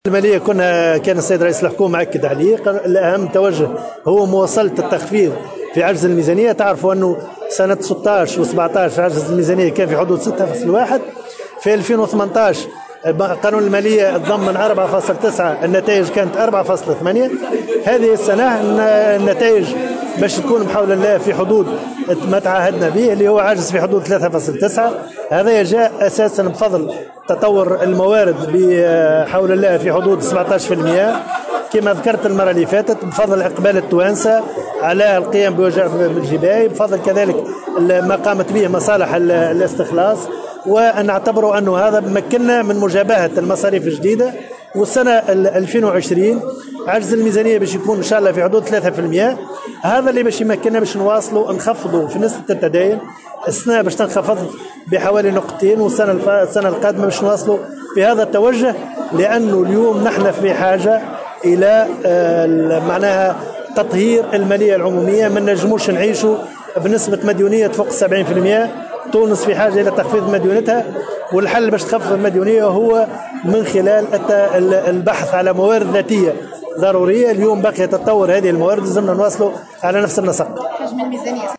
أكد وزير المالية، رضا شلغوم في تصريح لمراسلة الجوهرة "اف ام" اليوم الإثنين على هامش مشاركته بالمنتدى 24 لمنظمة التأمين الافريقية بتونس، أن مشروع ميزانية الدولة لسنة 2020 سيكون في حدود 47 مليار دينار مقابل 40،662 مليار دينار في 2019".